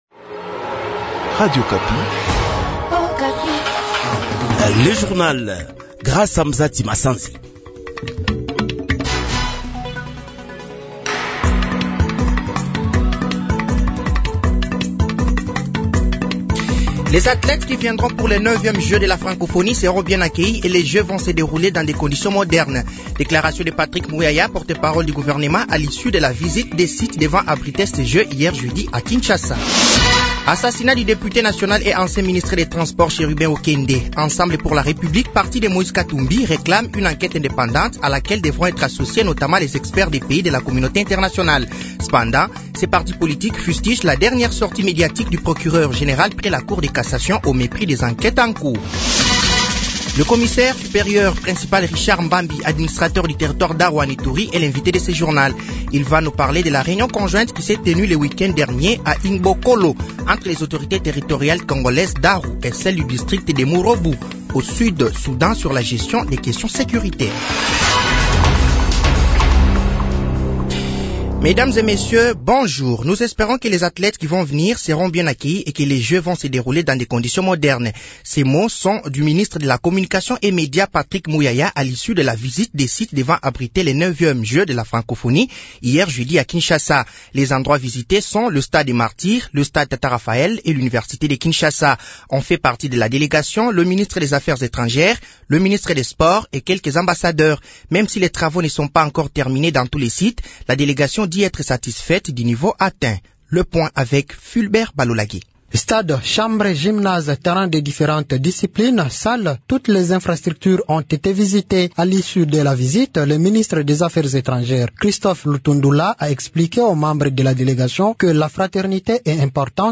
Journal français de 6h00 de ce vendredi 21 juillet 2023